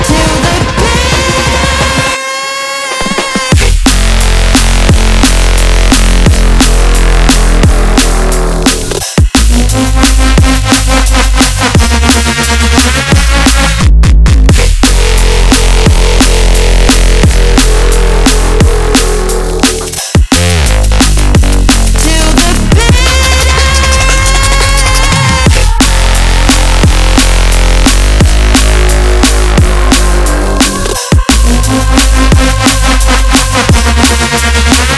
Jungle Drum'n'bass Dance
Жанр: Танцевальные